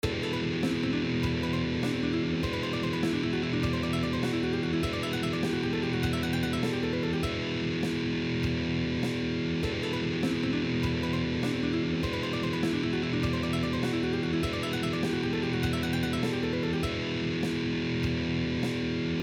Exercise 4: 2 String Pattern Ascending
2-String-Pattern-Skipping-Exercise-Ascending.mp3